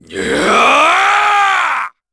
Chase-Vox_Casting2_kr.wav